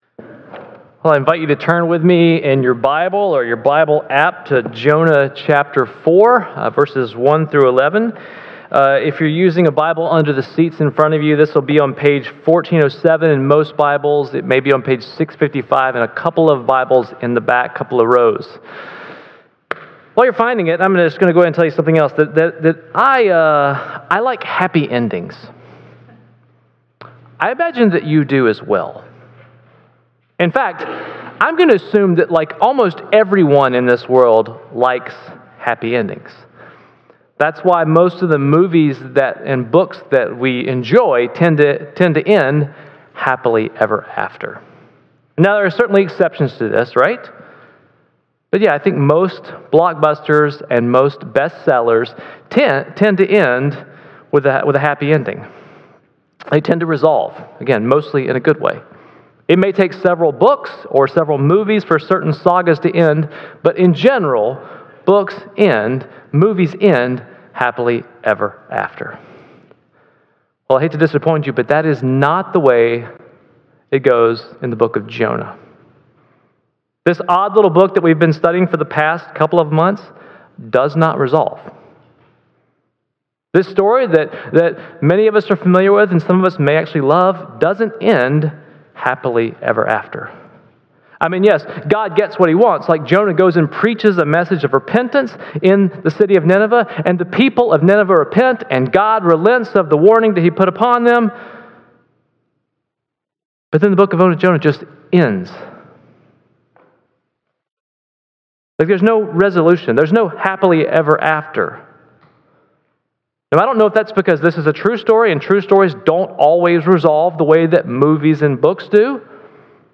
This week's sermon wraps up our series on Jonah. We will we dive into Jonah 4:1-11 and be Surprised by Grace.